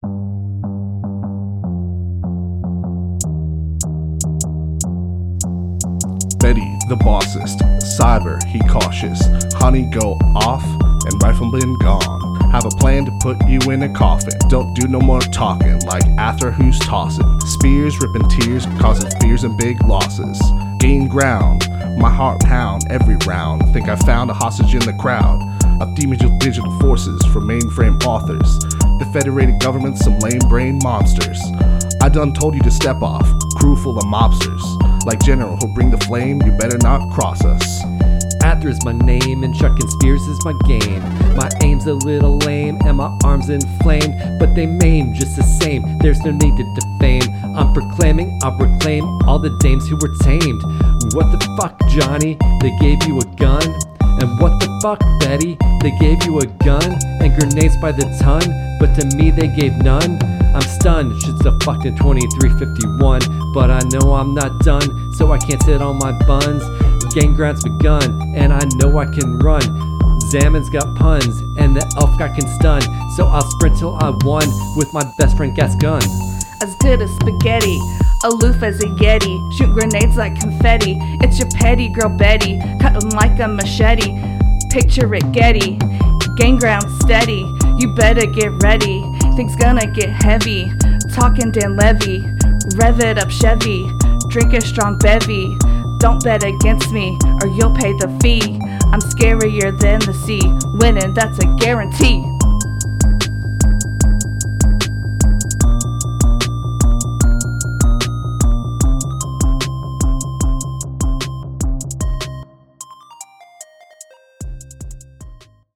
Rap from Episode 75: Gain Ground – Press any Button